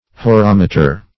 Search Result for " horometer" : The Collaborative International Dictionary of English v.0.48: Horometer \Ho*rom"e*ter\, n. [Gr.